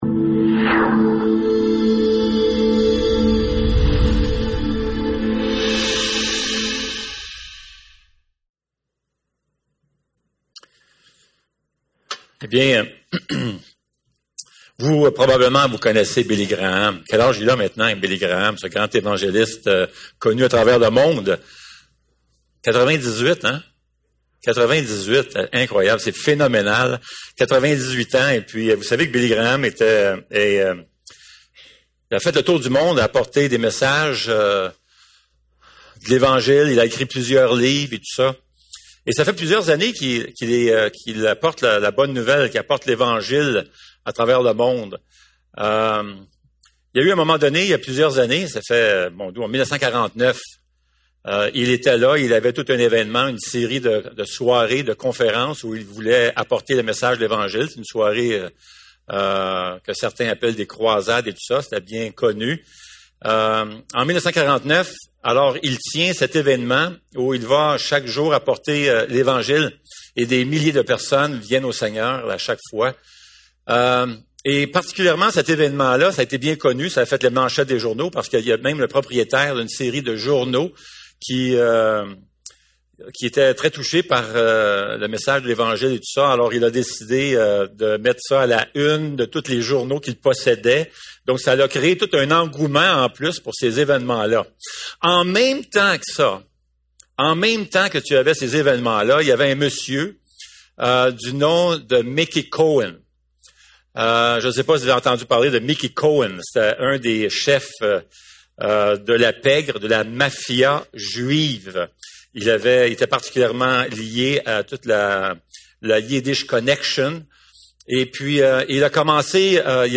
1 Pierre 4:1-6 Service Type: Célébration dimanche matin Topics